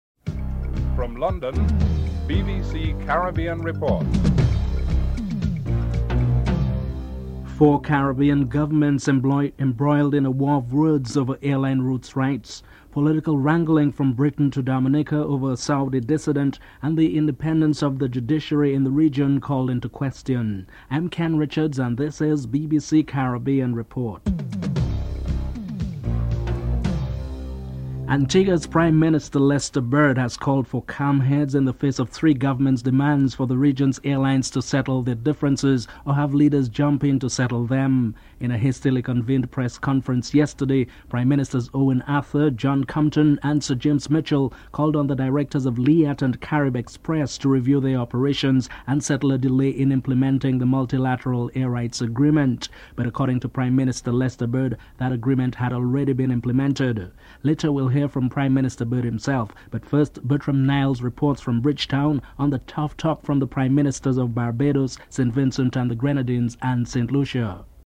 1. Headlines (00:00-00:26)
2. Four Caribbean governments embroiled in a war of words over airline routes rights. Prime Minister James Mitchell and Prime Minister Lester Bird are interviewed.